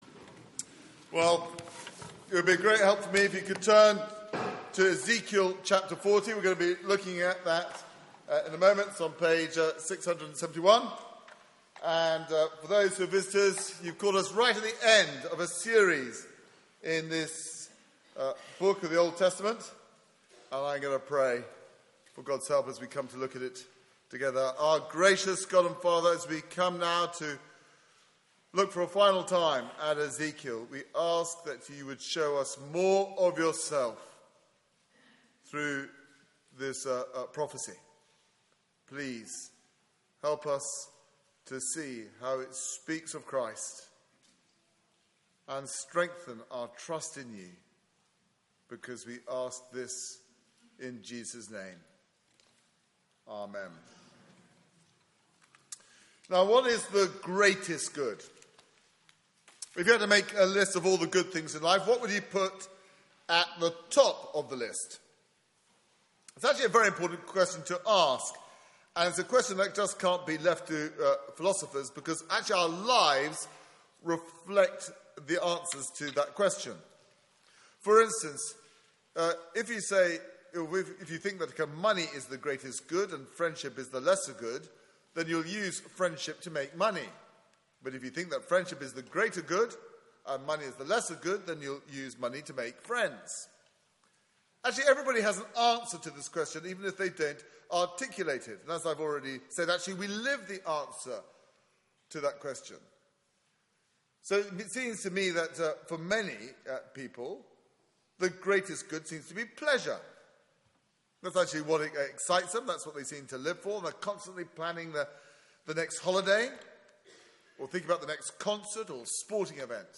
Media for 9:15am Service on Sun 28th Feb 2016
Passage: Ezekiel 40-48 Series: Ezekiel - Hope for the Hopeless Theme: A vision of salvation Sermon